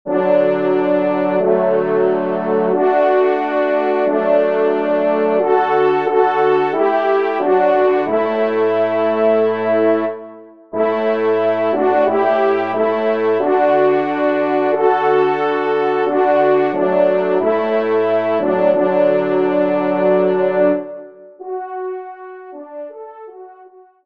Genre : Musique Religieuse pour Trois Trompes ou Cors
ENSEMBLE